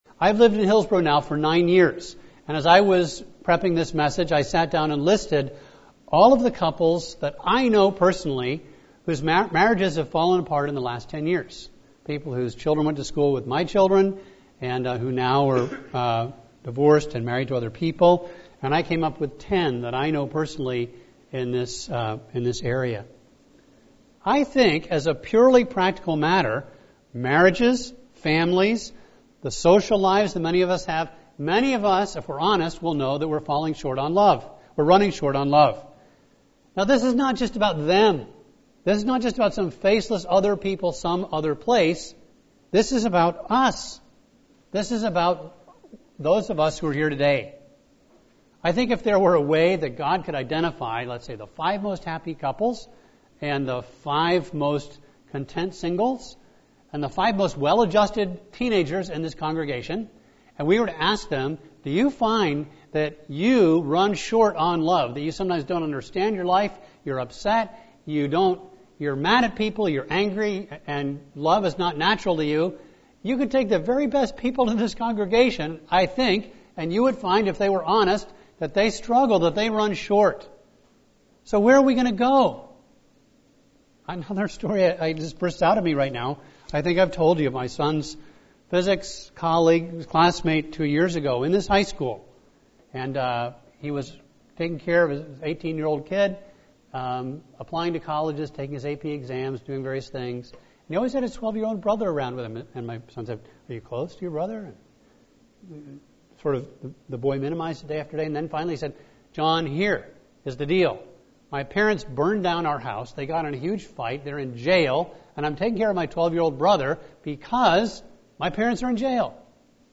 A message from the series "40 Days of Love."